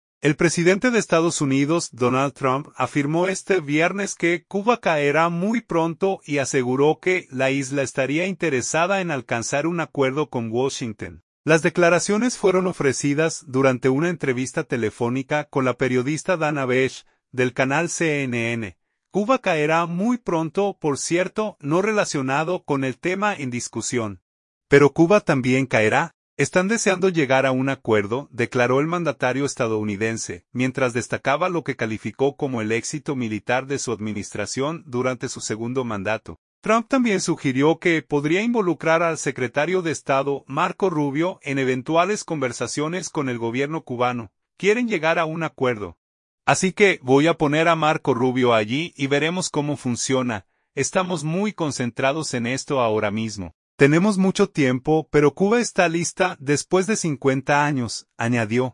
Las declaraciones fueron ofrecidas durante una entrevista telefónica con la periodista Dana Bash, del canal CNN.